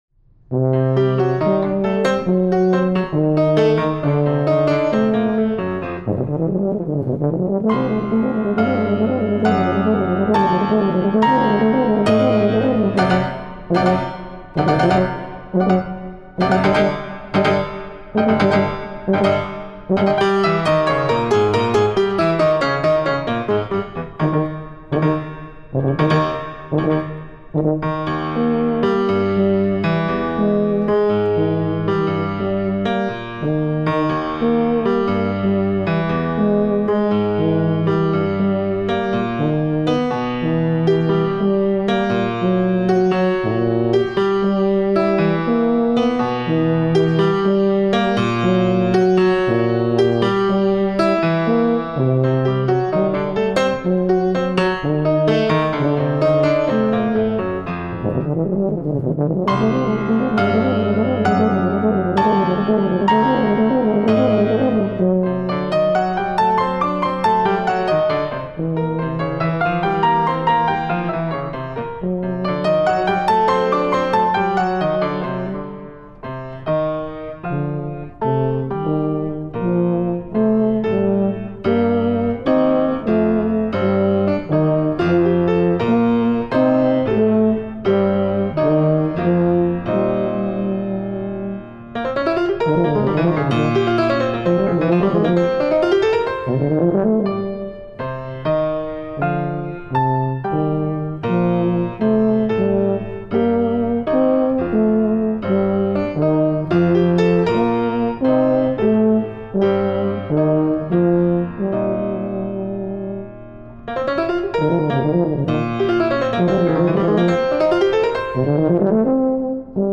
OctoNervosa Beta for euphonium and piano (2005)